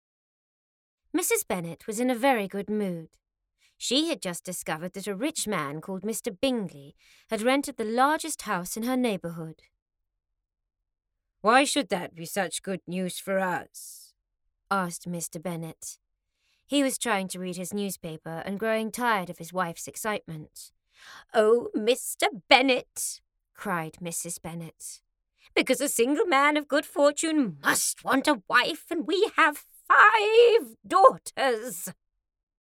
a sample of the UK audiobook!)